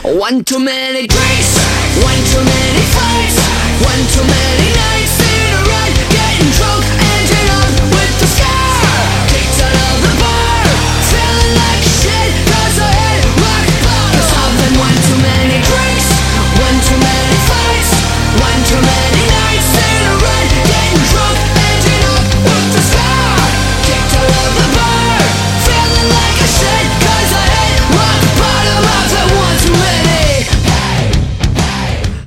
Рок
Заводная рок-песня